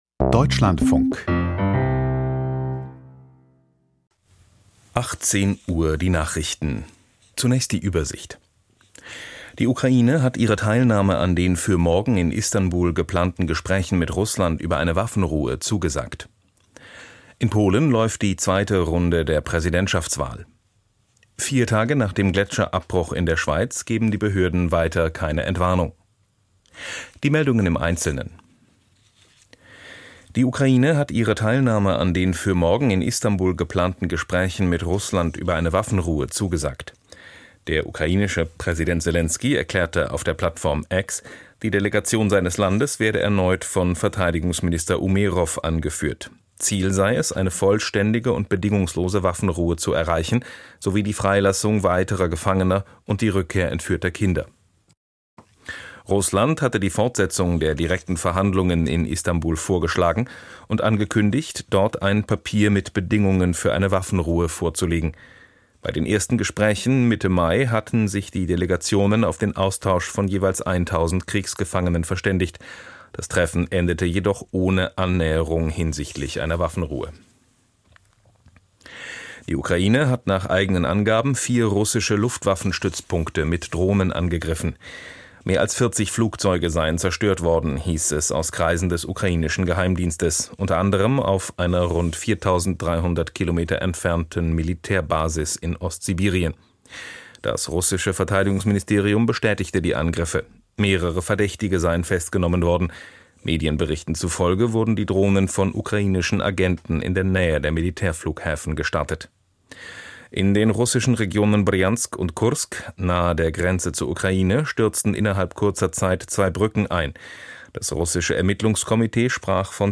Die Nachrichten vom 01.06.2025, 18:00 Uhr
Aus der Deutschlandfunk-Nachrichtenredaktion.